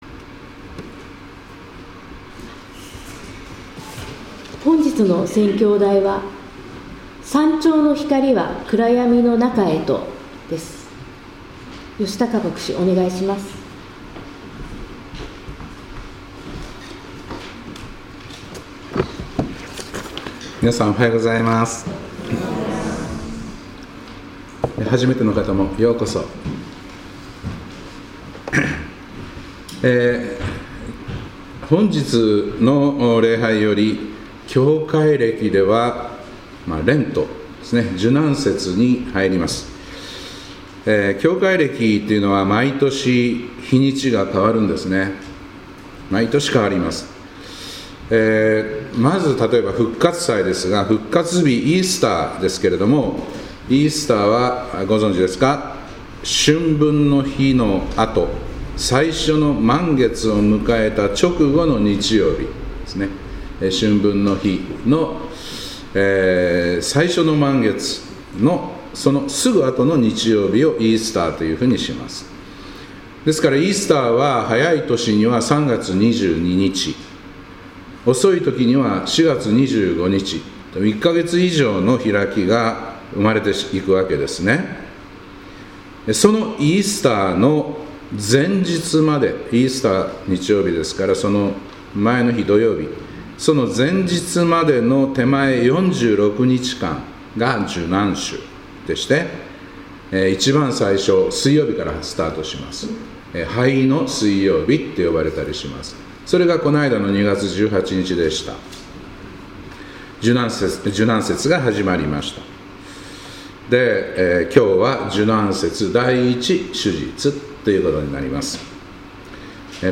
2026年2月22日礼拝「山頂の光は暗闇の中へと」